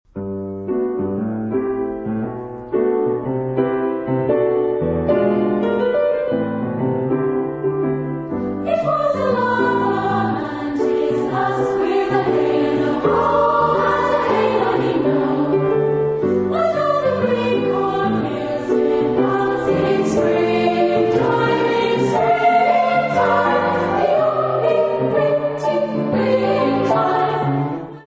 Mood of the piece: rhythmic ; humorous ; light
Type of Choir: SA  (2 women voices )
Instrumentation: Piano  (1 instrumental part(s))
Tonality: G major